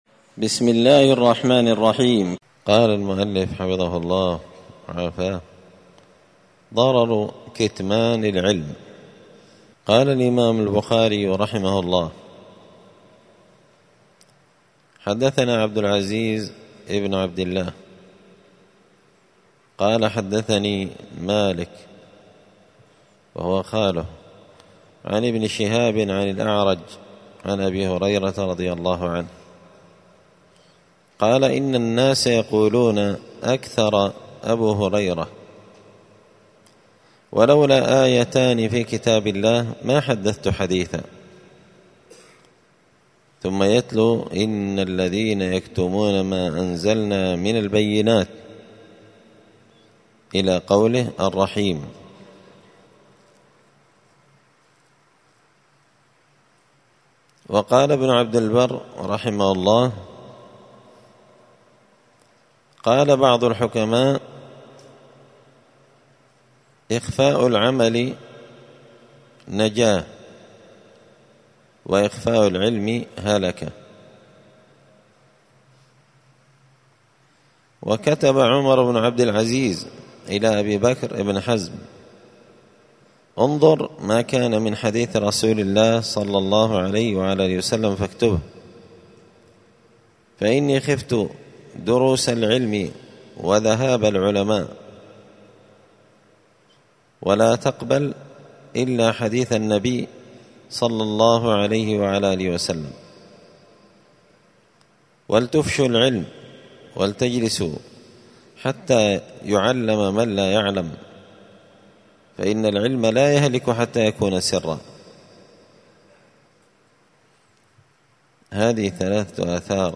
دار الحديث السلفية بمسجد الفرقان بقشن المهرة اليمن
*الدرس الثلاثون (30) ضرر كتمان العلم*